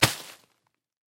Звуки шагов по песку
Звук прыжка по речному песку